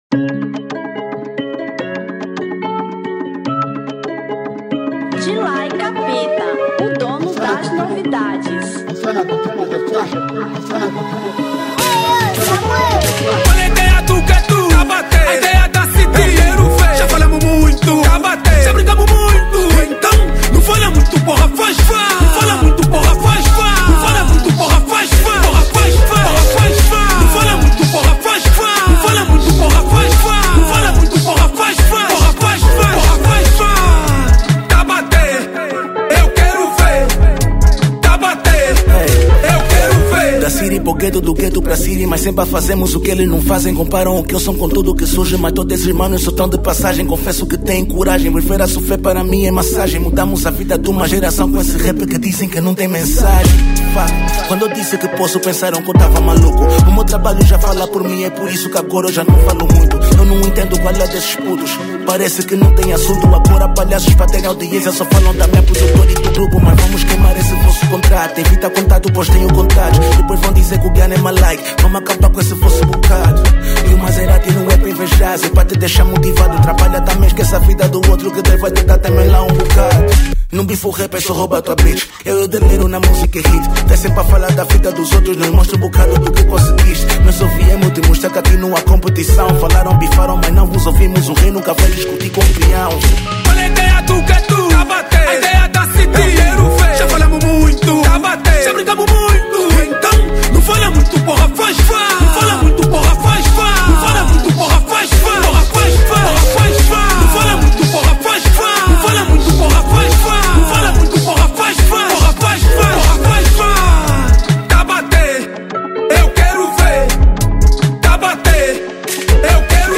Kuduro 2025